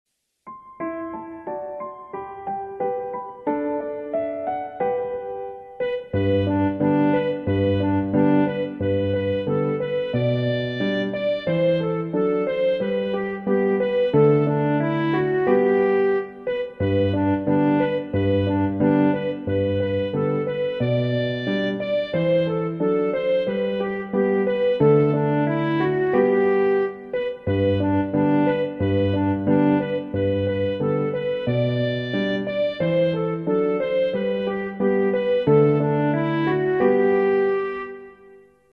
Караоке.
Сл. народні,